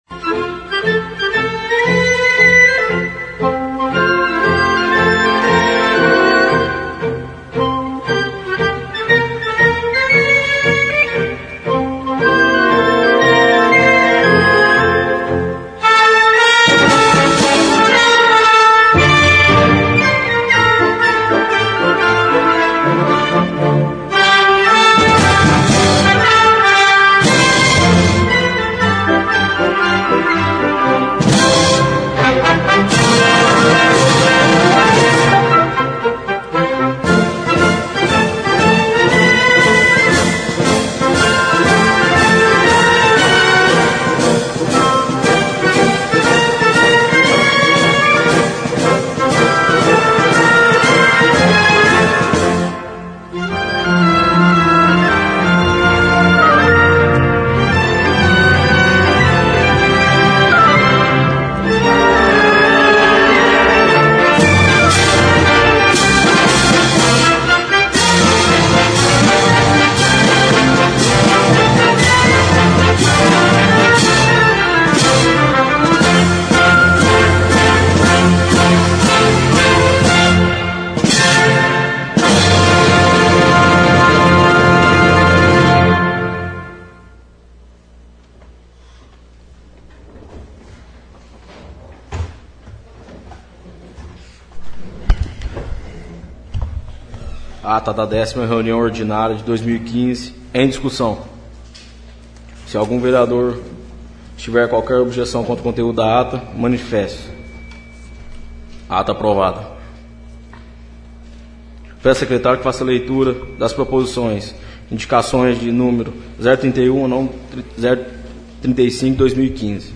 Sessão Ordinária e Solene - 17/08/15